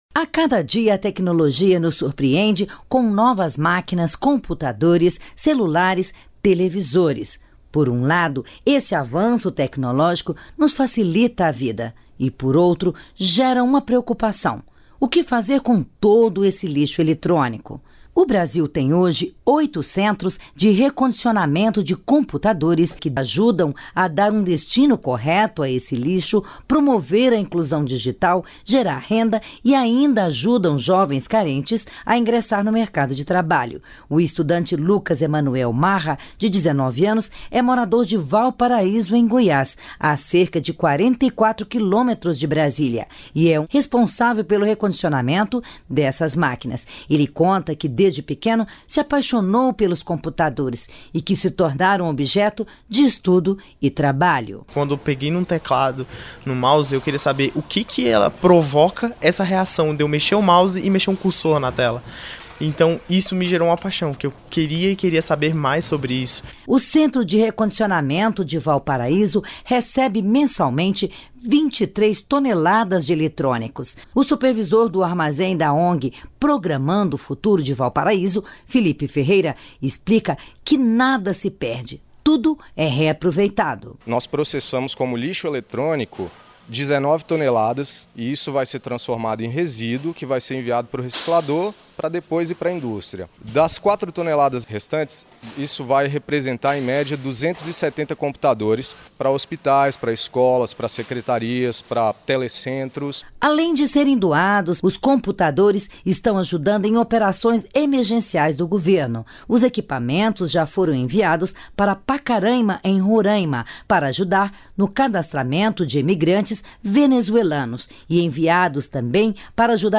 MATÉRIA ORIGINALMENTE PUBLICADA NO SITE REDE NACIONAL DE RÁDIO A PARTIR DO LINK